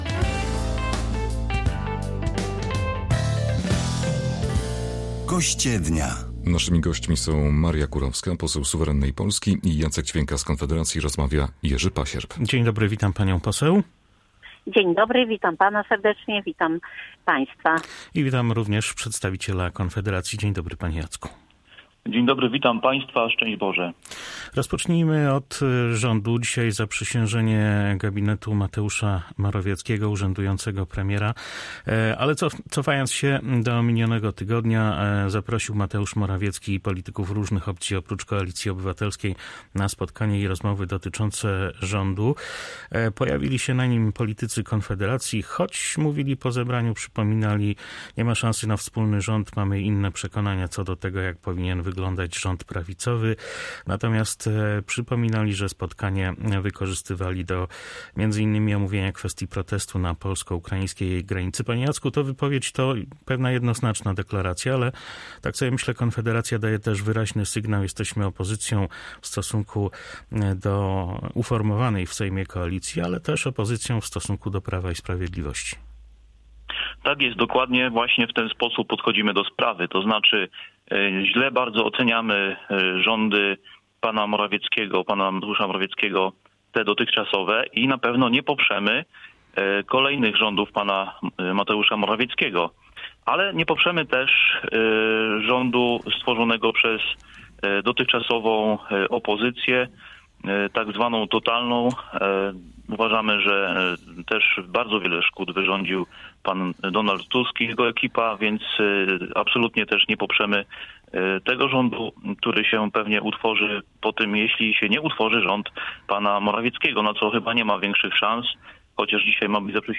Propozycję powołania trzech sejmowych komisji śledczych, odwołania członków komisji do spraw badania wpływów rosyjskich w Polsce oraz działań dotyczących powołania kolejnego rządu Mateusza Morawieckiego skomentowali na naszej antenie podkarpaccy politycy, przedstawiciele ugrupowań reprezentowanych w parlamencie.